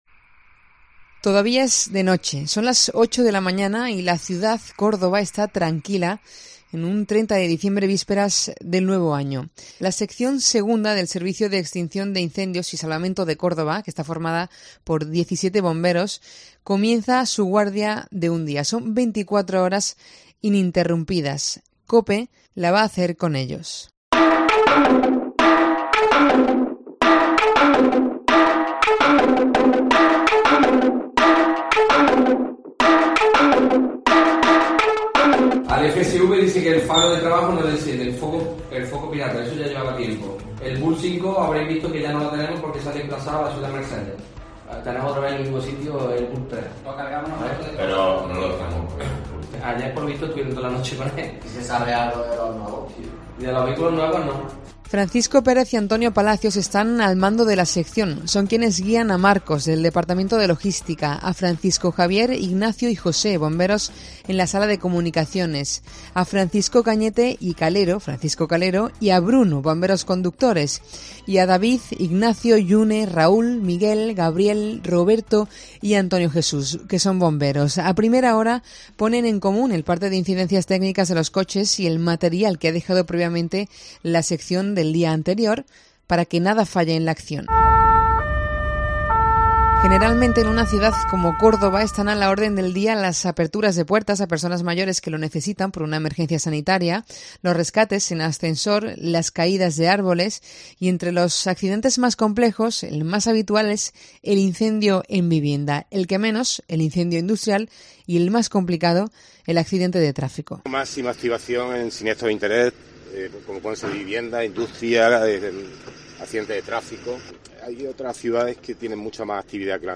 24 horas con los bomberos de Córdoba: así suena una guardia completa en el Parque Central